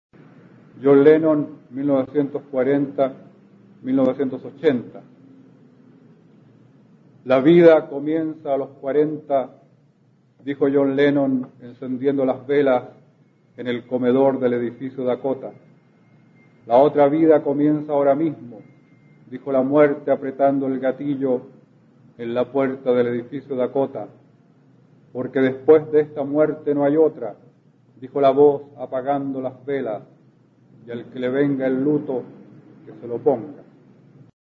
Escucharás al poeta chileno Óscar Hahn, perteneciente a la Generación del 60, recitando su poema John Lennon (1940-1980), del libro "Mal de amor" (1981).